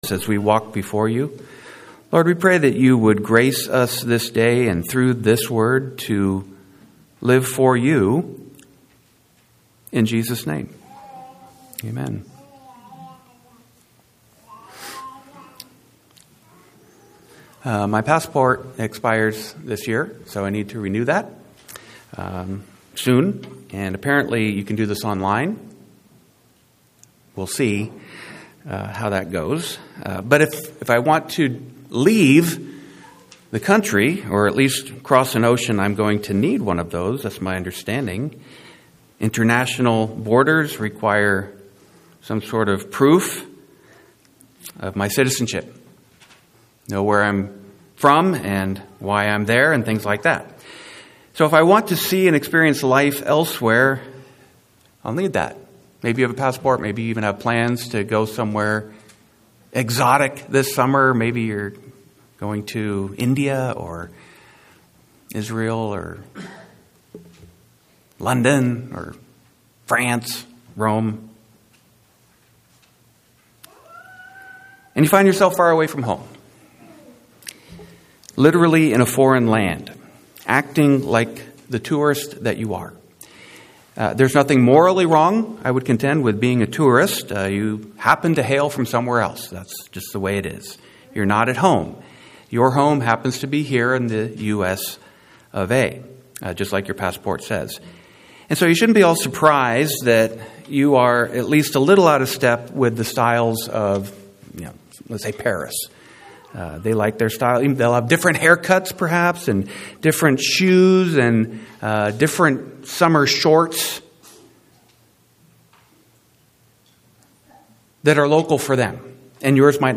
Occasional Sermons